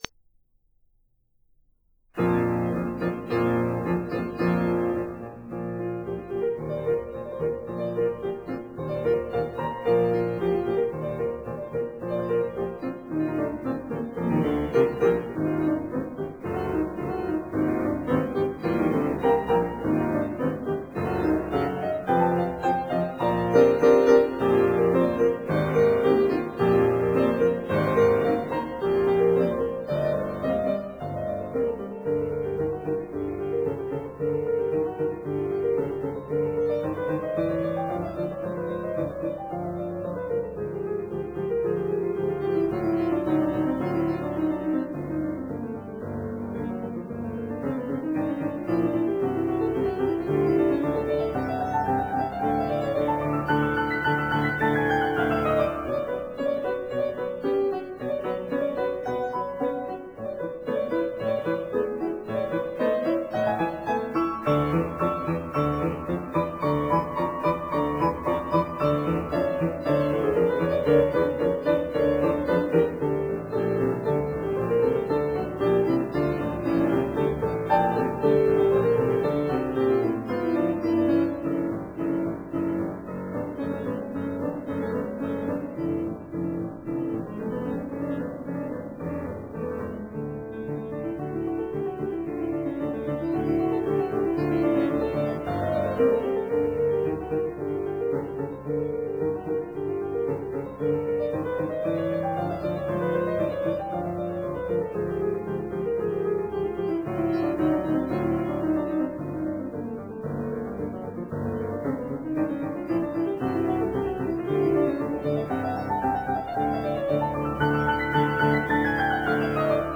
Ballet Suite
piano